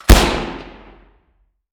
weap_juliet_launch_atmo_int_01.ogg